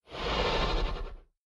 sounds / mob / horse / zombie / idle2.mp3